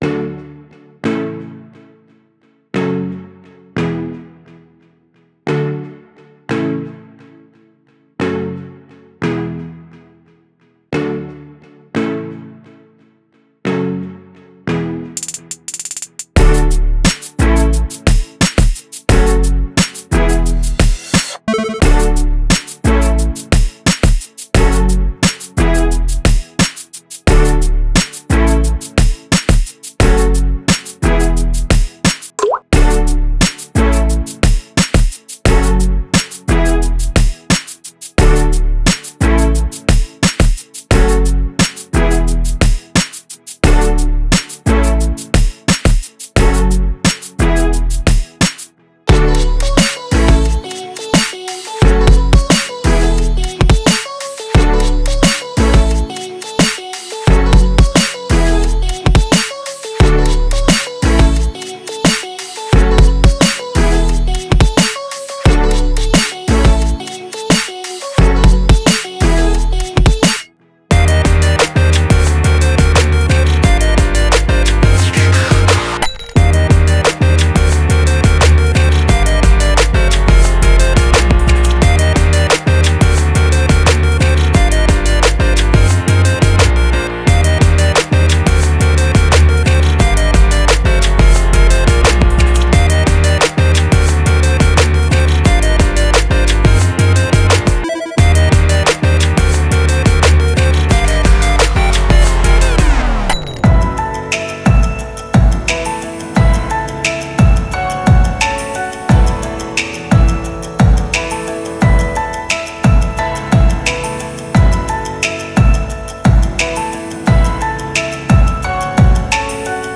■OFF VOCAL